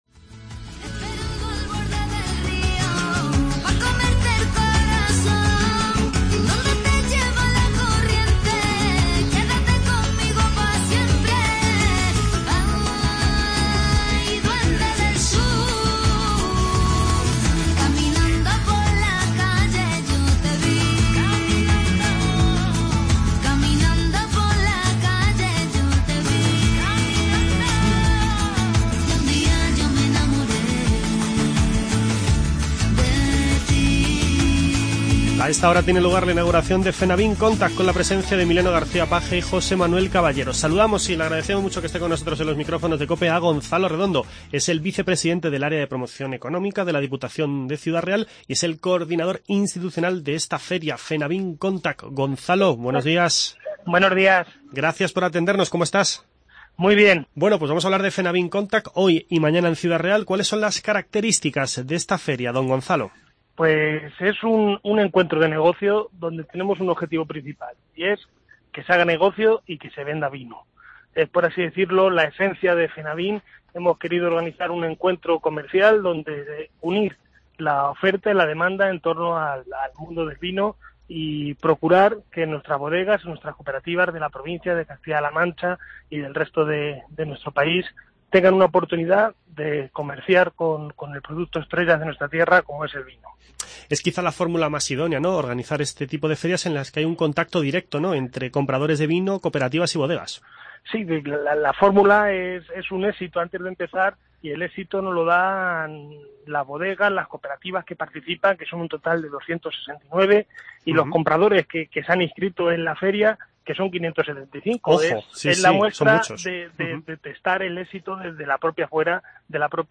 Escuche la entrevista con Gonzalo Redondo, vicepresidente de Promoción Económica de la Diputación Provincial de Ciudad Real y coordinador institucional de Fenavin Contact.